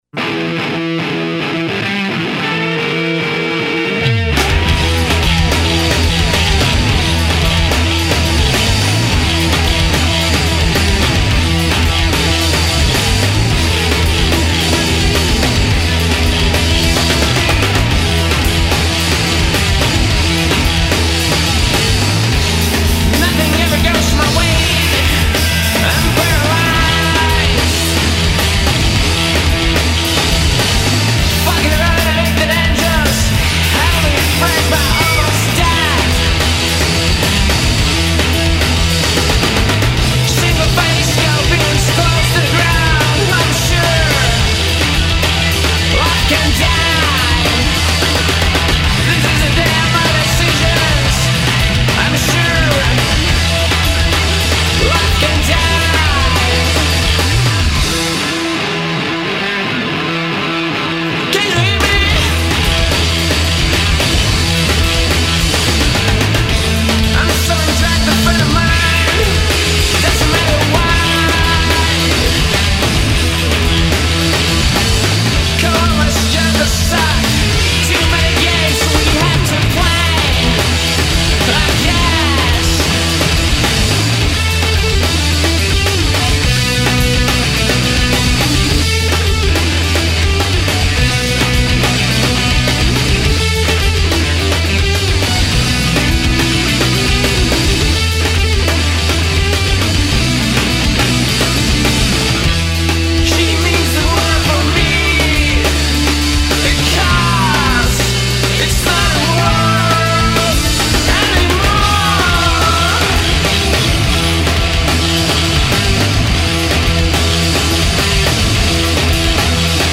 dalla Repubblica Ceca la No Wave anni '80 dei